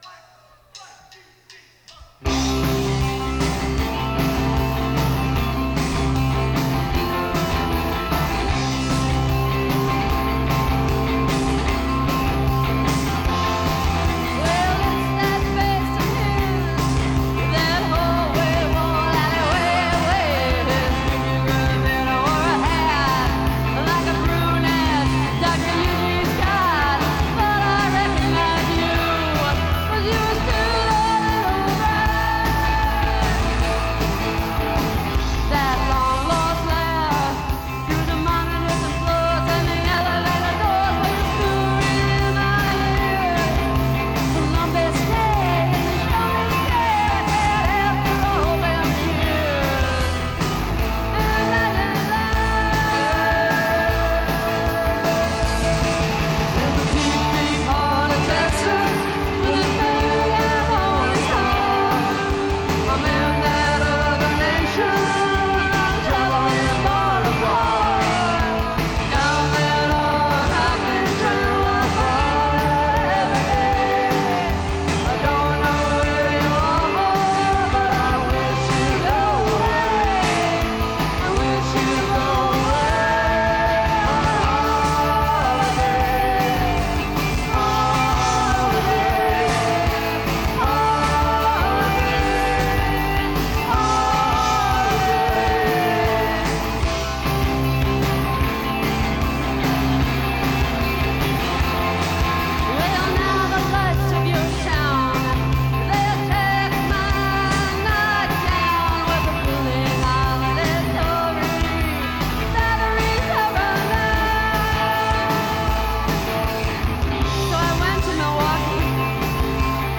Live from the Cabaret in July 1986.